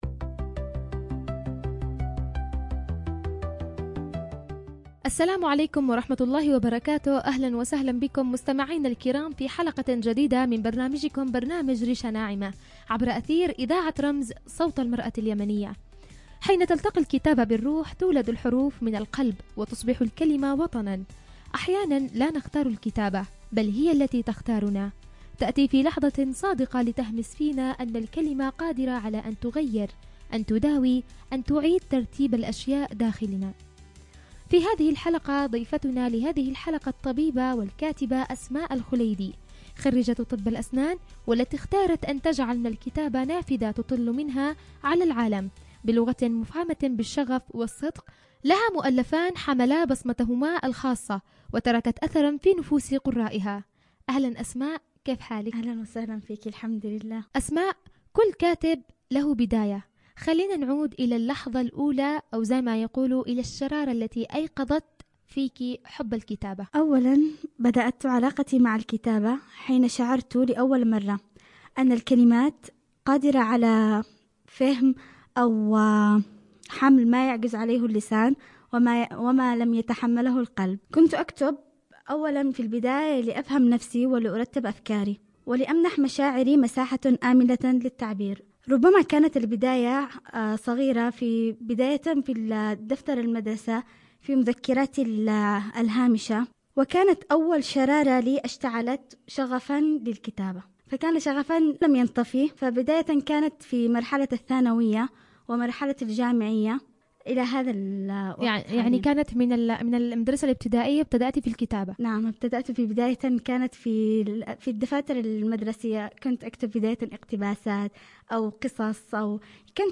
يدار حوار لطيف حول جمعها بين مهنة الطب وهواية الكتابة.
عبر أثير إذاعة رمز